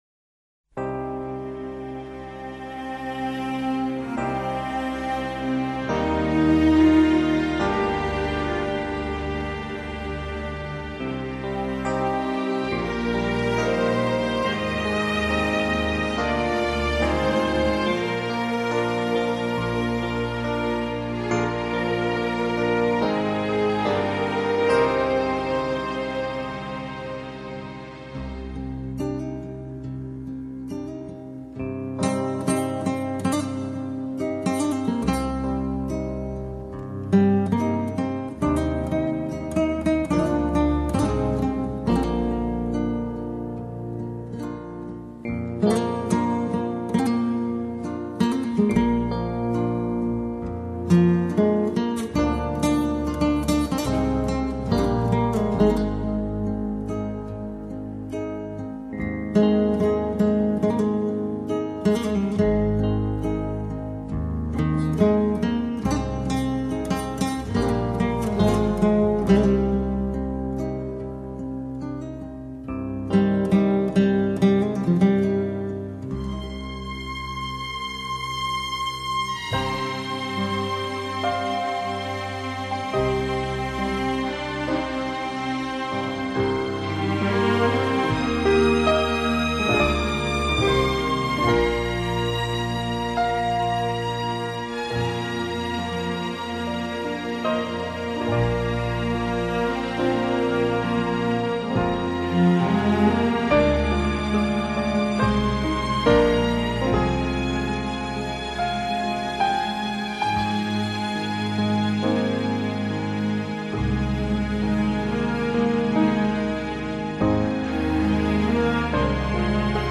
گروهی کر متشکل از ۳ خانم و یک آقا